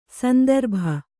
♪ sandarbha